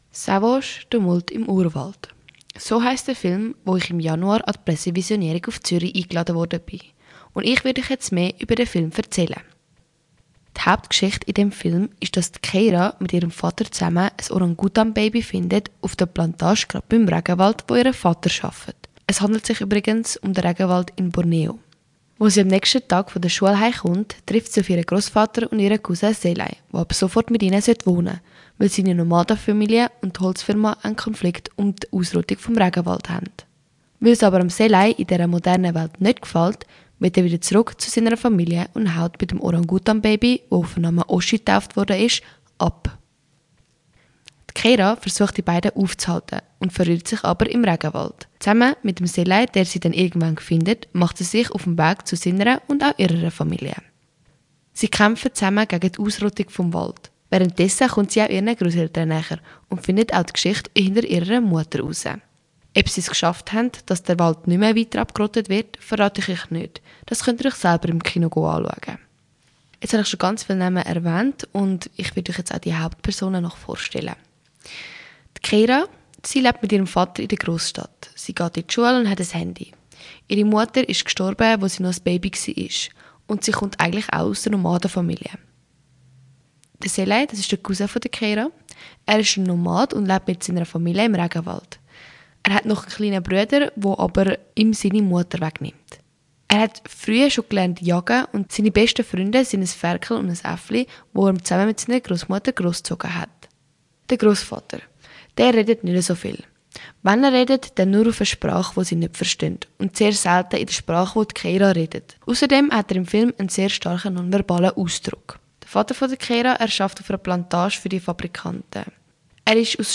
Ein besonderes Highlight ist mein Interview